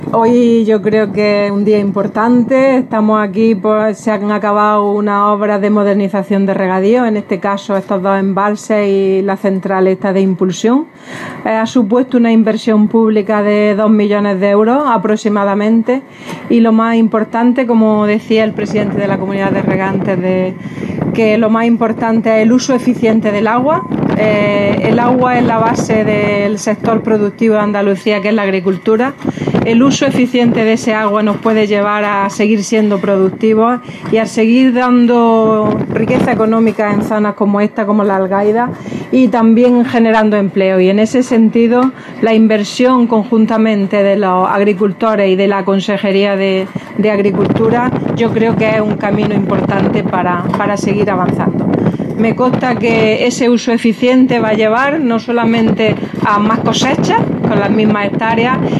Declaraciones consejera sobre obras Balsa El Tablazo